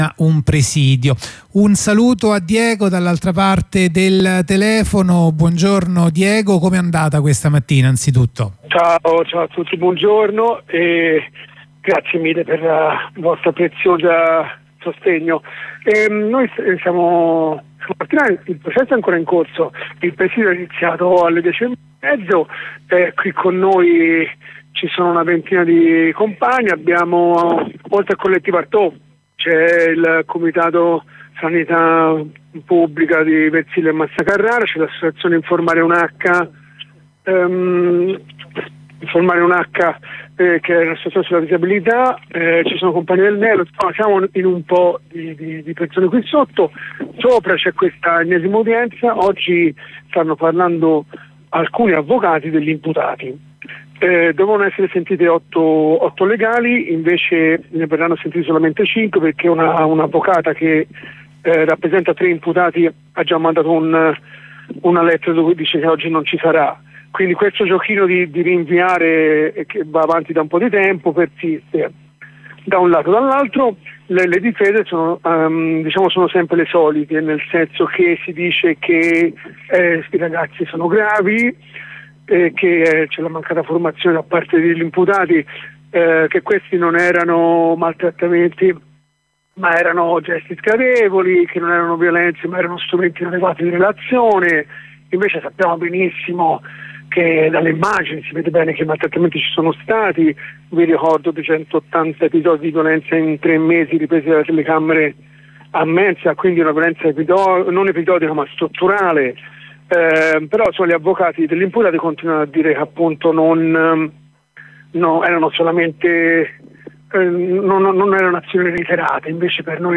Anche oggi presidio di fronte al tribunale di Pisa per seguire la vicenda della stella maris, che dovrebbe concludersi nel corso del prossimo autunno. Ci fornisce tutti gli aggiornamenti un compagno del collettivo Antonin Artaud